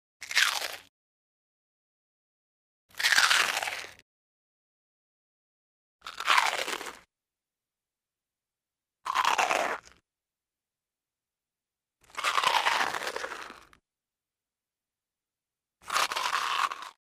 Звуки хруста чипсов
На этой странице собраны натуральные звуки хруста чипсов: от открытия упаковки до аппетитного хруста при надкусывании.
Все звуки записаны в высоком качестве и доступны бесплатно.